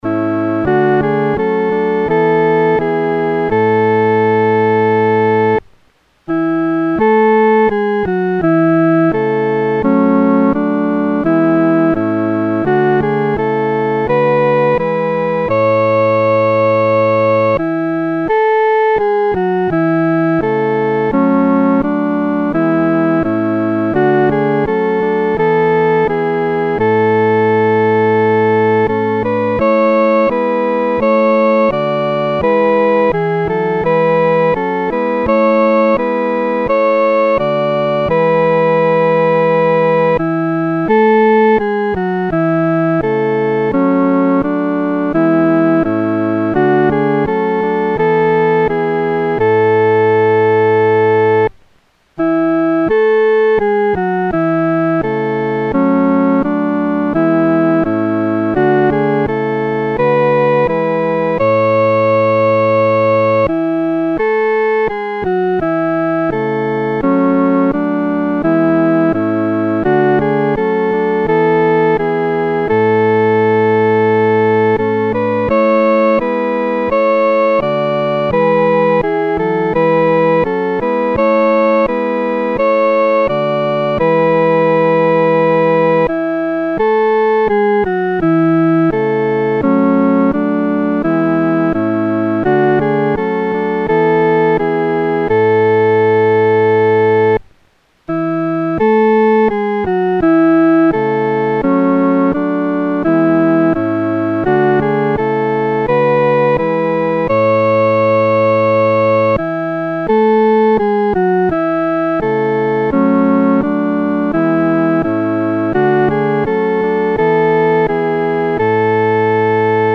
独奏（第一声）